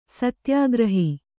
♪ satyāgrahi